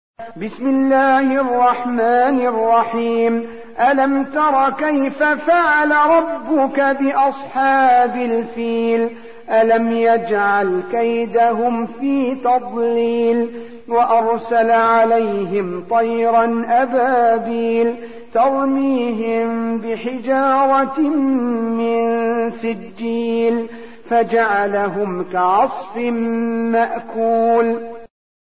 تحميل : 105. سورة الفيل / القارئ عبد المنعم عبد المبدي / القرآن الكريم / موقع يا حسين